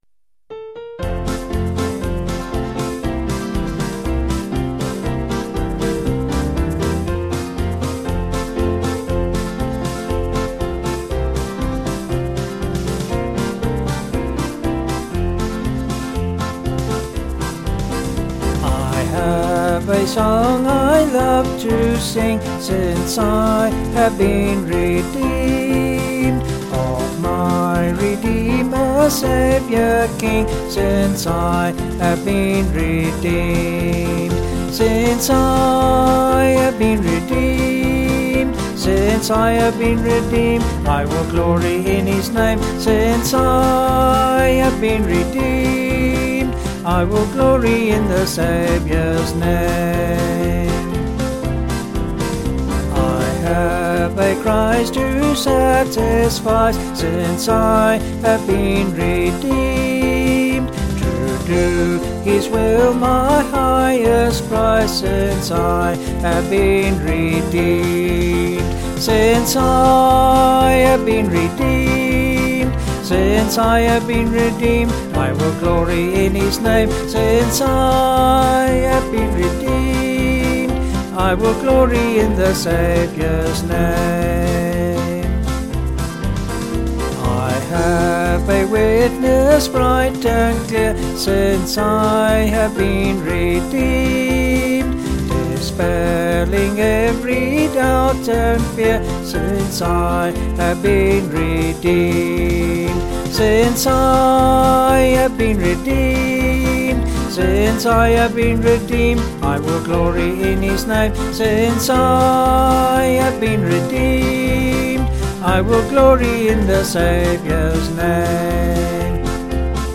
Vocals and Band   703.4kb Sung Lyrics